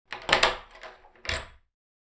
دانلود صدای در چوبی 8 از ساعد نیوز با لینک مستقیم و کیفیت بالا
جلوه های صوتی
برچسب: دانلود آهنگ های افکت صوتی اشیاء دانلود آلبوم صدای کوبیدن در چوبی از افکت صوتی اشیاء